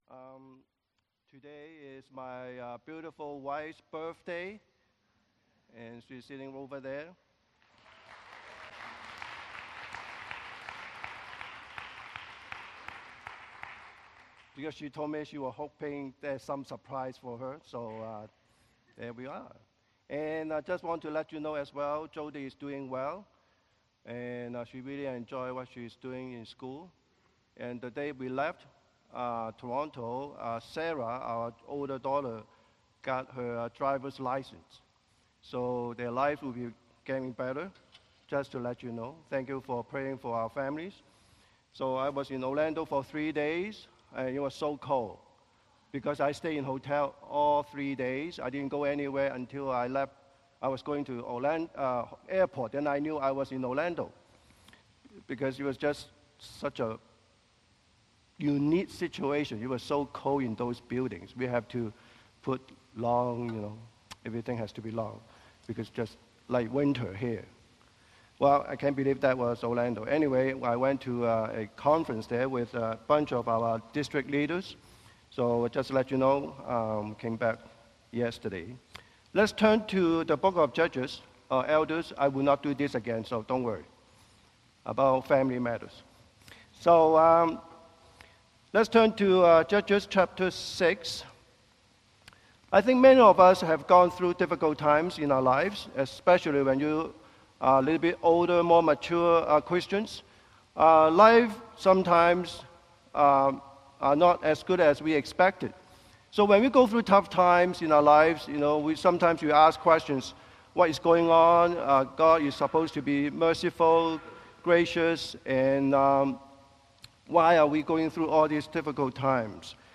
Passage: Judges 6:11-18 Service Type: Sunday Morning Service